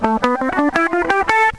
On a donc comme intervalles : 1T, 1/2T, 1T, 1T, 1/2T, 1T et 1T.
Gamme Mineure de La (cliquez pour écouter)